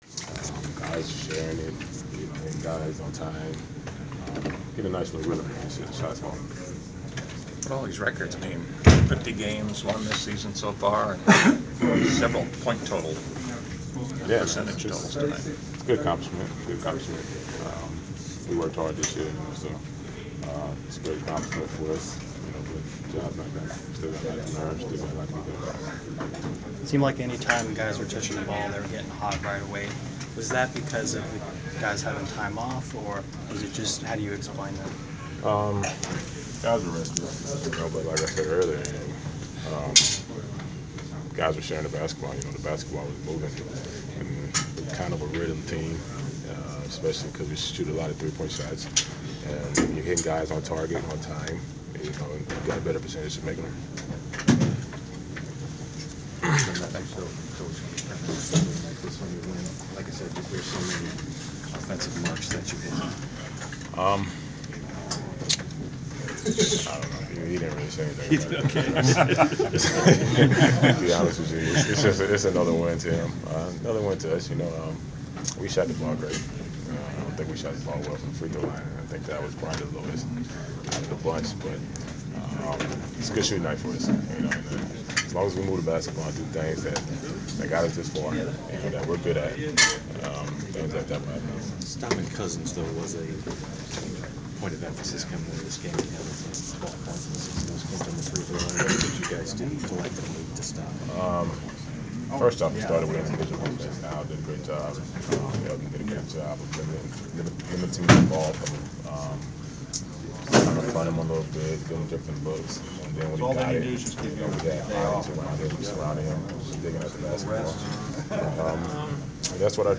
Inside the Inquirer: Postgame presser with Atlanta Hawk Paul Millsap (3/9/15)
We attended the postgame presser of Atlanta Hawks’ forward Paul MIllsap following his team’s 130-105 home win over the Sacramento Kings on Mar. 9.